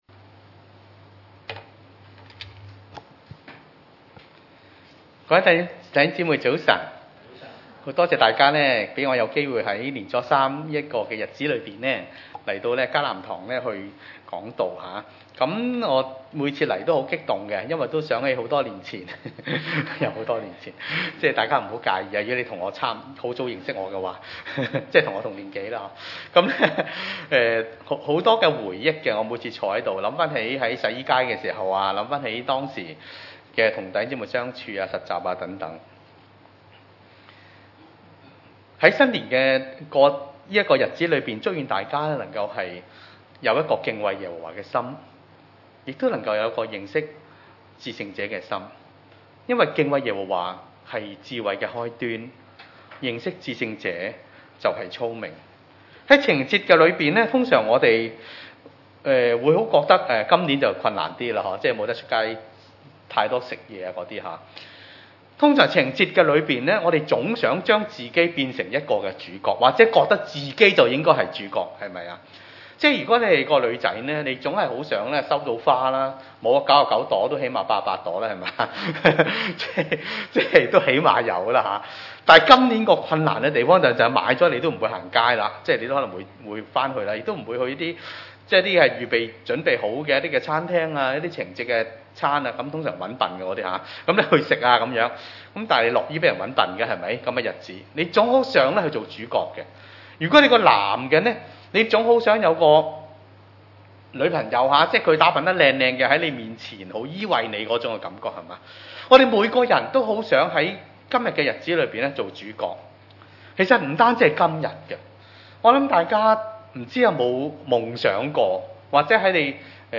約翰福音1：19-37 崇拜類別: 主日午堂崇拜 約翰所作的見證記在下面：猶太人從耶路撒冷差祭司和利未人到約翰那裡，問他說：你是誰？